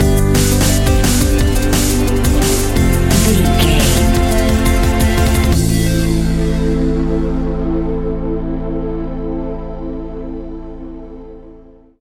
royalty free music
Aeolian/Minor
Fast
hypnotic
industrial
frantic
aggressive
synthesiser
drums
electronic
sub bass
synth leads